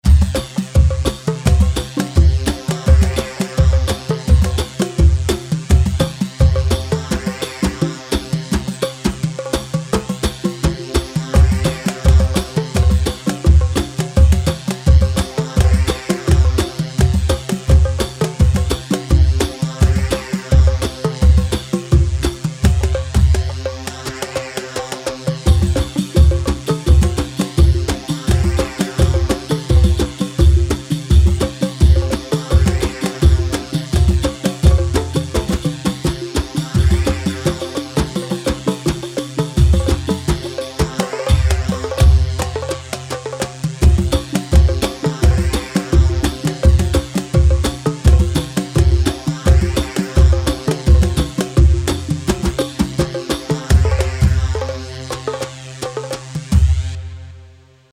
Khbeiti 4/4 170 خبيتي
Khbeiti-170-4-4-mix.mp3